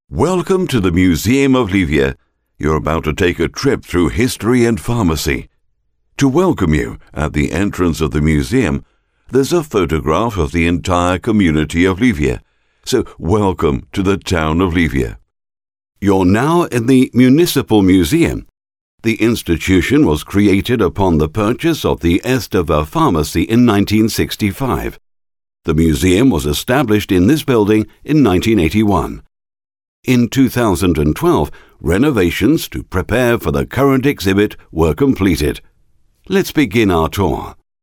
Native speakers
Engels (vk)